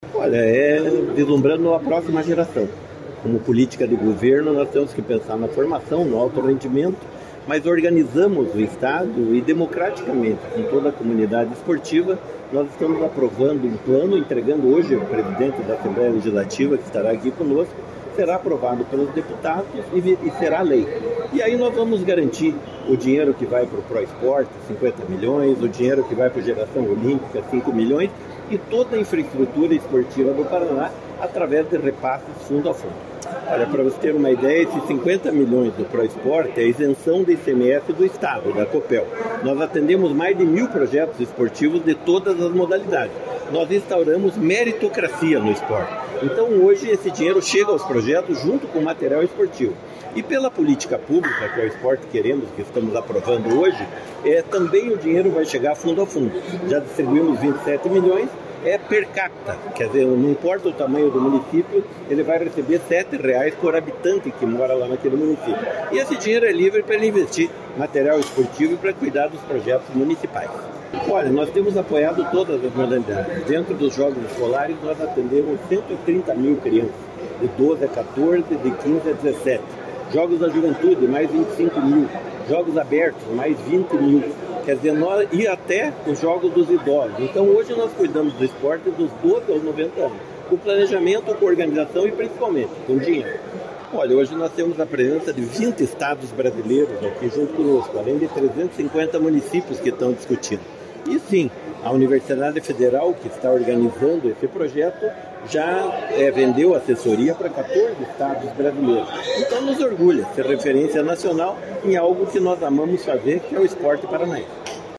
Sonora do secretário do Esporte, Hélio Wirbiski, sobre o Plano Decenal do Esporte do Paraná